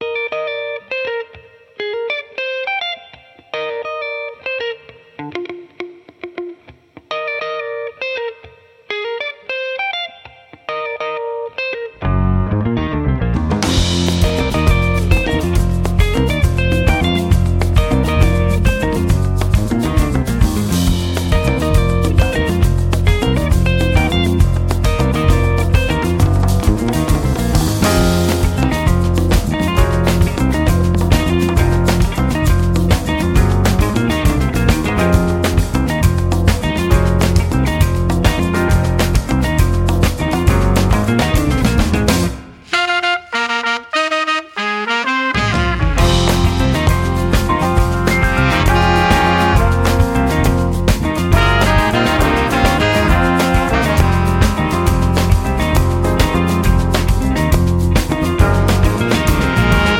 Funk Jazz